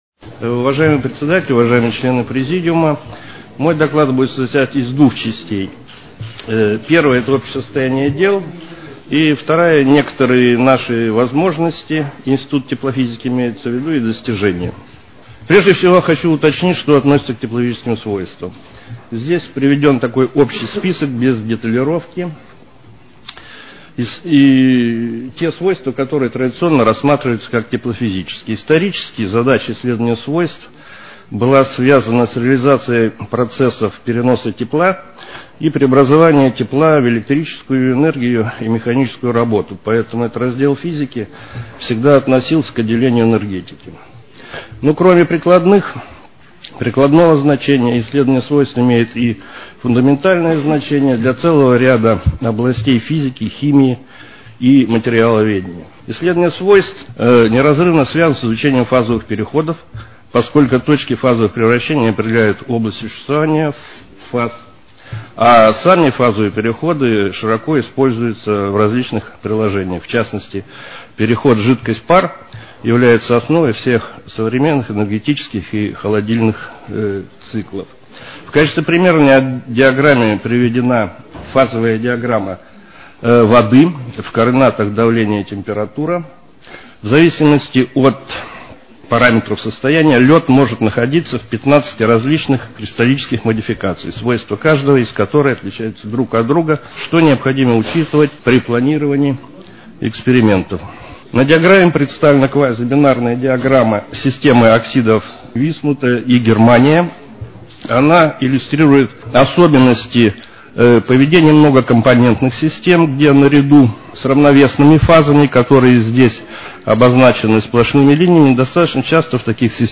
Аудиозапись доклада в формате mp3 – в прикрепленном файле.